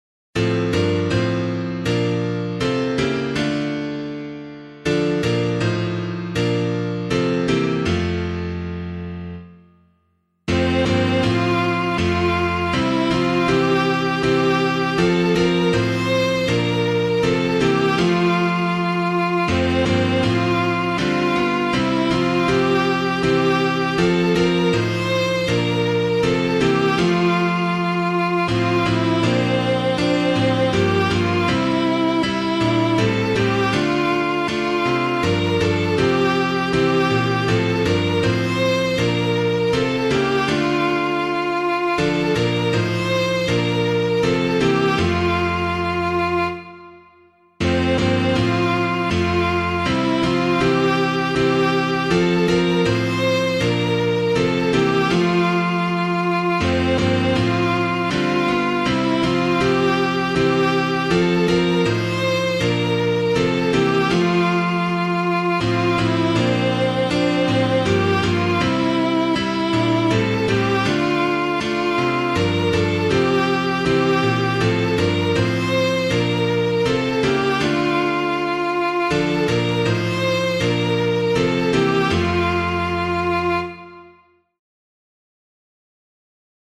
Hymn suitable for Catholic liturgy
Inspiration: W żłobie leży , Polish carol.
Infant Holy Infant Lowly [Reed - W ZLOBIE LEZY] - piano.mp3